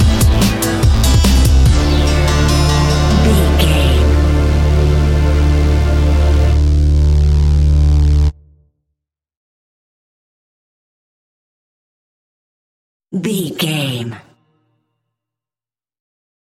Trance Party Music Theme Stinger.
Aeolian/Minor
Fast
energetic
hypnotic
drum machine
synthesiser
uptempo
synth leads
synth bass